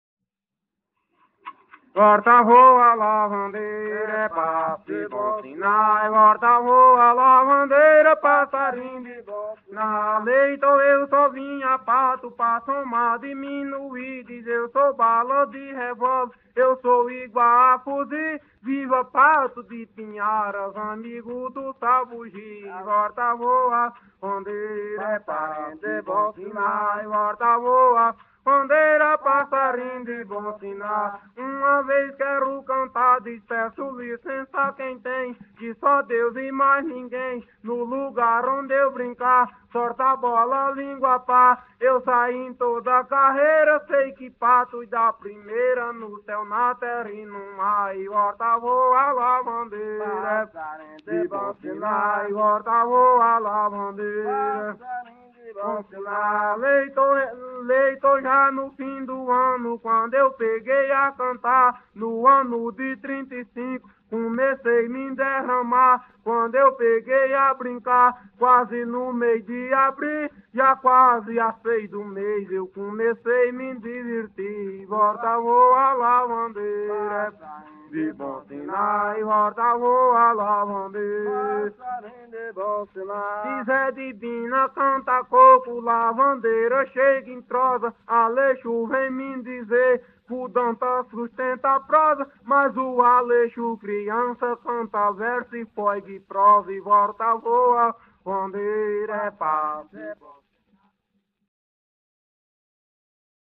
Coco martelo – “”Passo de bom sinal”” - Acervos - Centro Cultural São Paulo